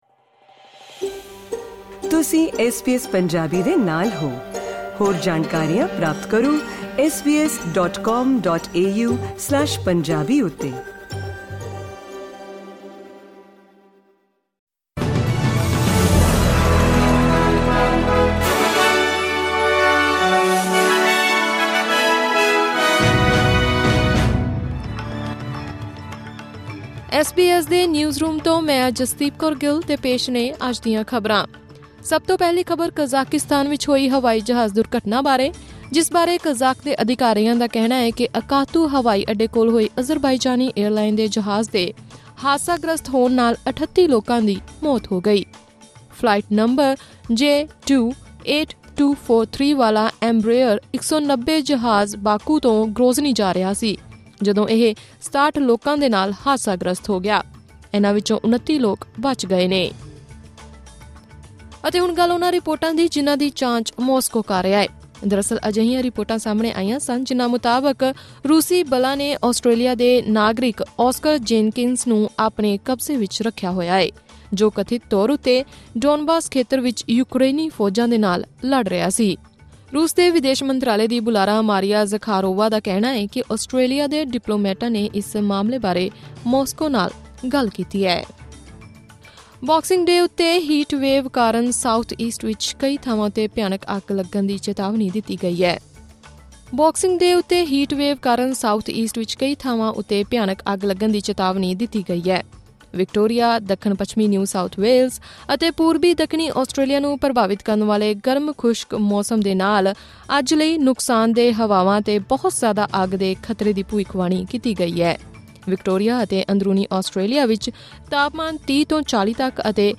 ਖ਼ਬਰਨਾਮਾ: ਅਕਤਾਉ ਨੇੜੇ ਅਜ਼ਰਬਾਈਜਾਨੀ ਏਅਰਲਾਈਨਰ ਹਾਦਸੇ ਵਿੱਚ 38 ਲੋਕਾਂ ਦੀ ਮੌਤ।